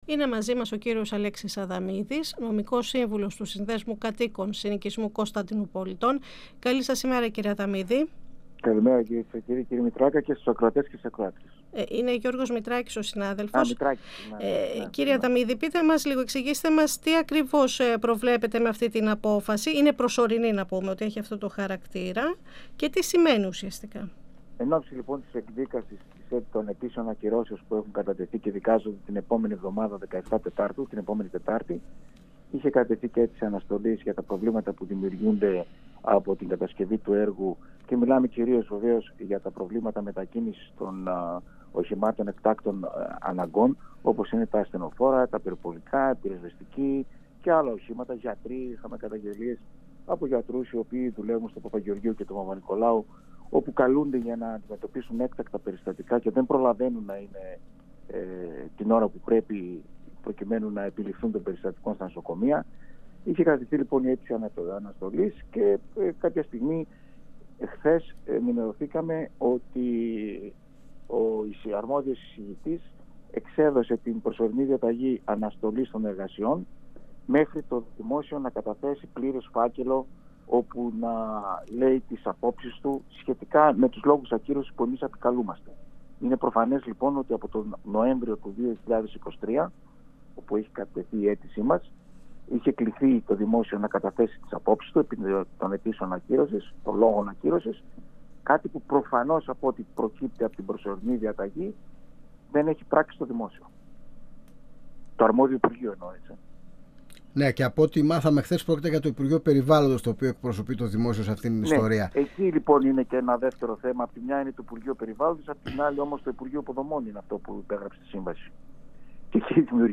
μιλώντας στην εκπομπή «Αίθουσα Σύνταξης» του 102FM της ΕΡΤ3.